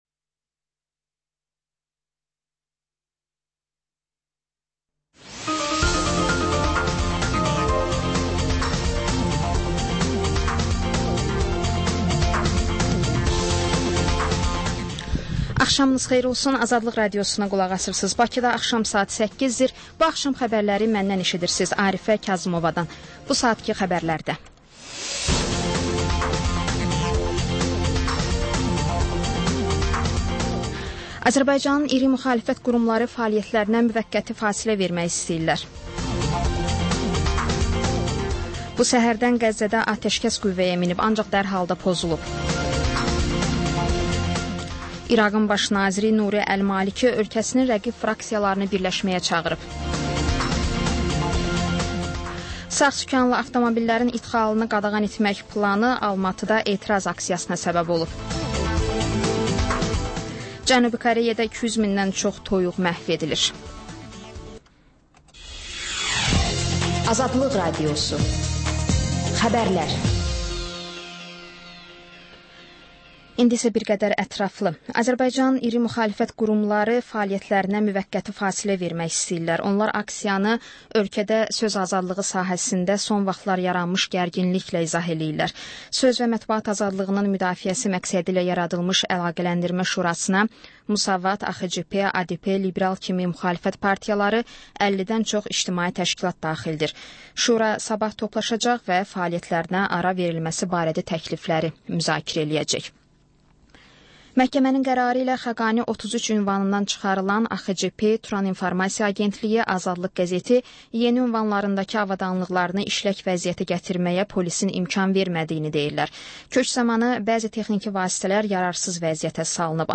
Xəbərlər, reportajlar, müsahibələr. İZ: Mədəniyyət proqramı.